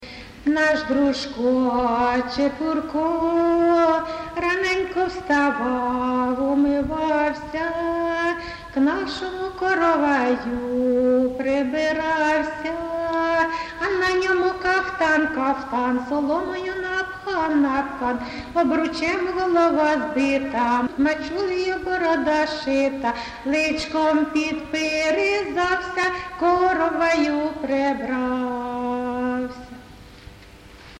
ЖанрВесільні
Місце записус-ще Зоря, Краматорський район, Донецька обл., Україна, Слобожанщина